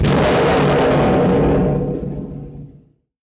受影响的拍子 " 14个音阶的拍子
描述：实验性噪音节拍
标签： 节拍 影响 噪声
声道立体声